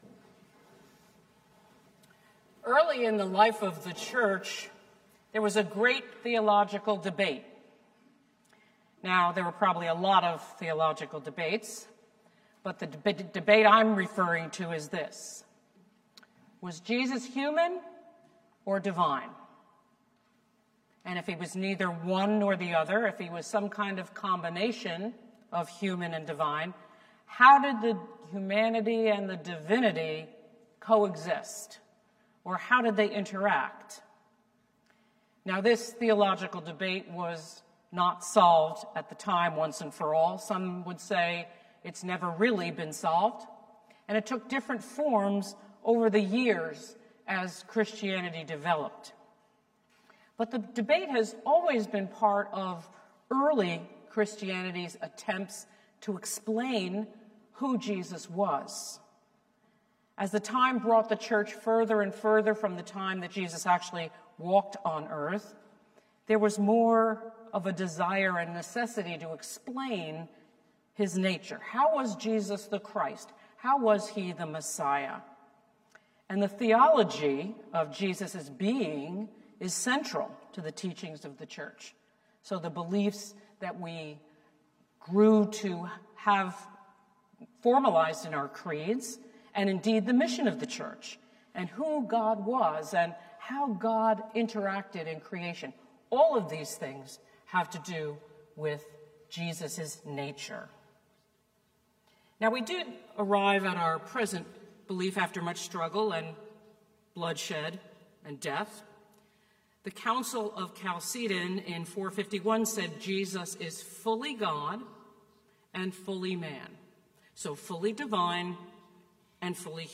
St-Pauls-HEII-9a-Homily-26MAR23.mp3